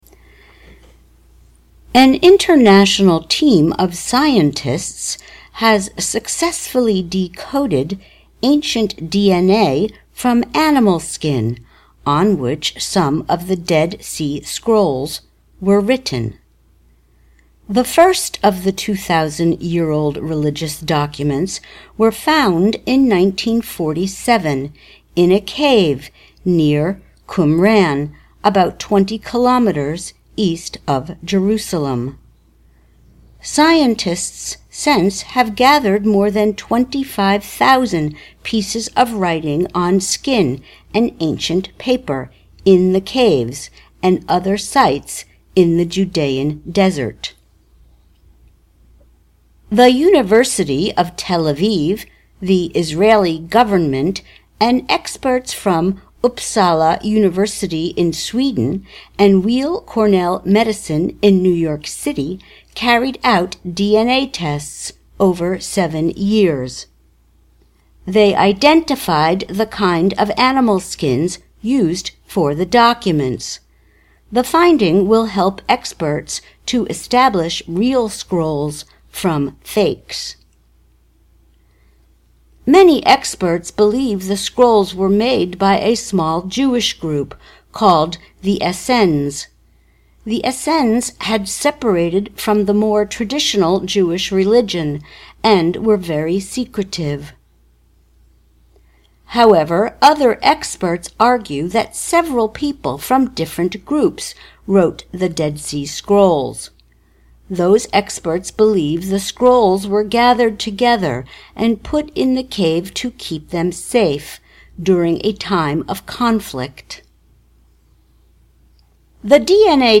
慢速英语:DNA帮助学者了解死海卷轴|慢速英语|慢速英语听力下载